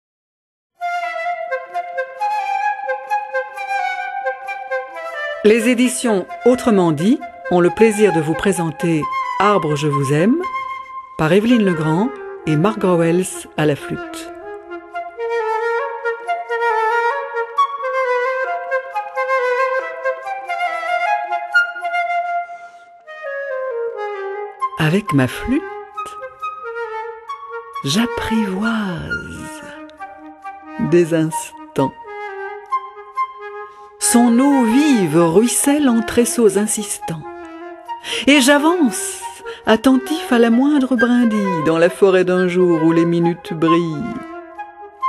Les Musiques de L.P. Drouet (1795-1873), choisies parmi les 25 Études pour la Flûte, s?épanouissent à merveile avec ces textes ; leur charme et leur sensibilité s?unissent et se magnifient l?un l?autre.